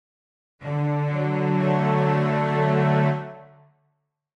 Those chords are tri ads - three notes played simultaneously.
Click to hear a Major Triad
major_chord.mp3